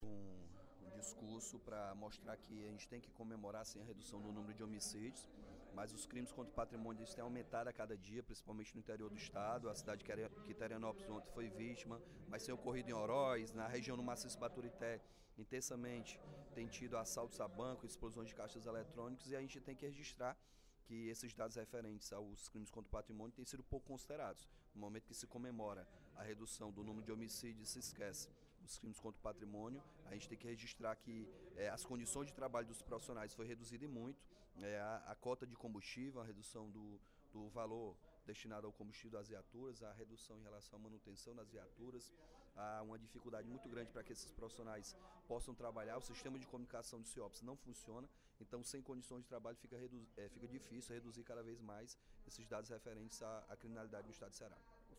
A redução do índice de homicídios no Ceará divulgado pela imprensa esta semana foi apontada pelo deputado Capitão Wagner (PR), durante o primeiro expediente da sessão plenária da Assembleia Legislativa desta quarta-feira (05/08).